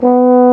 Index of /90_sSampleCDs/Roland LCDP12 Solo Brass/BRS_Baritone Hrn/BRS_Euphonium
BRS BARI B2.wav